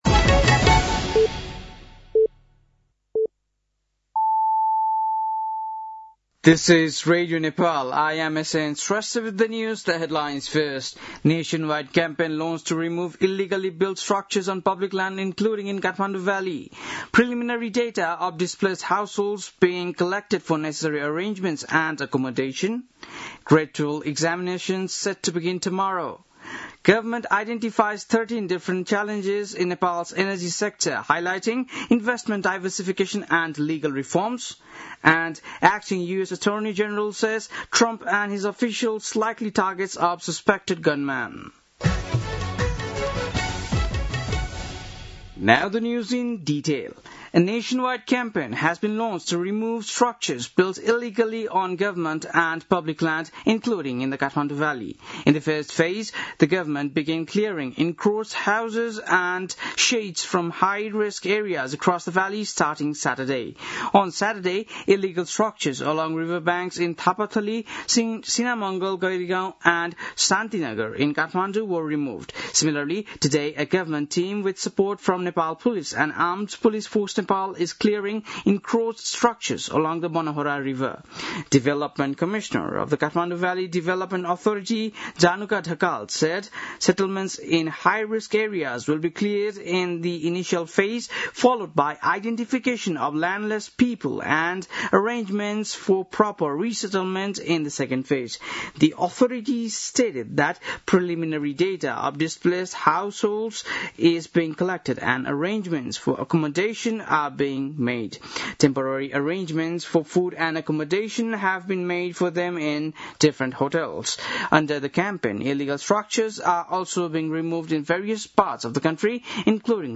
बेलुकी ८ बजेको अङ्ग्रेजी समाचार : १३ वैशाख , २०८३
8-pm-english-news-1-13.mp3